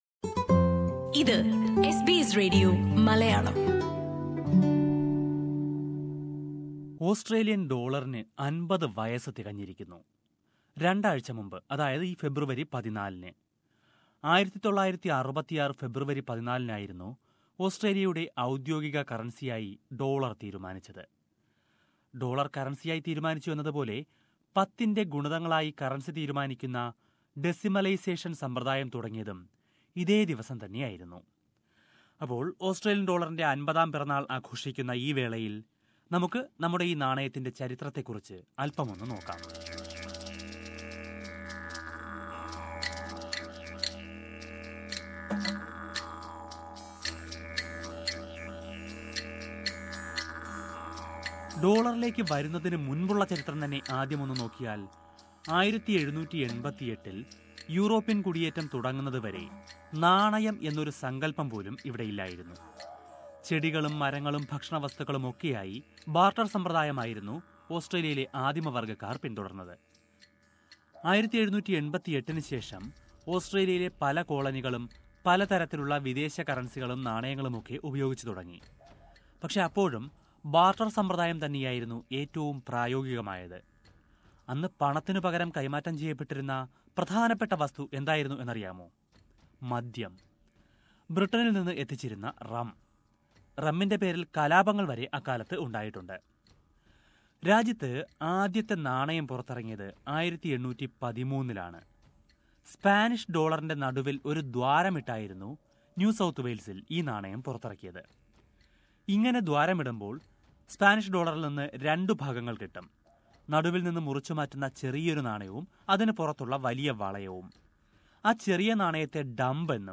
2016 marks the 50th year after Australian dollar became the currency of the country. Listen to a report on the history of Australian currencies.